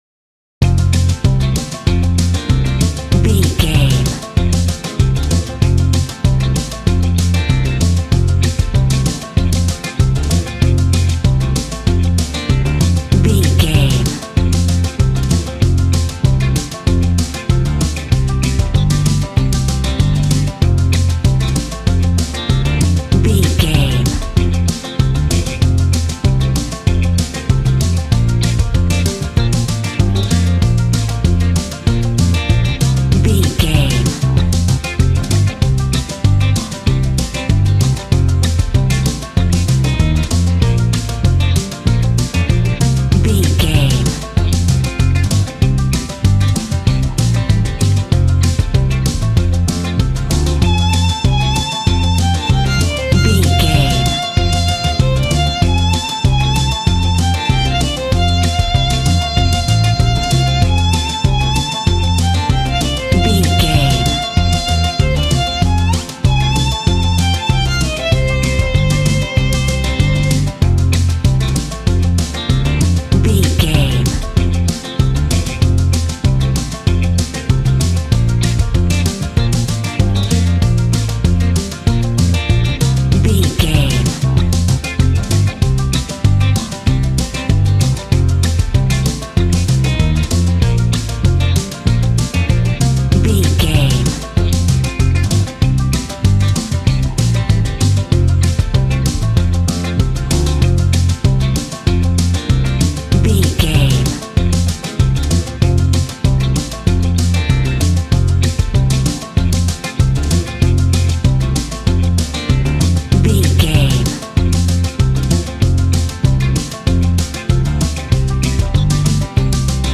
Ionian/Major
Fast
fun
bouncy
positive
double bass
drums
acoustic guitar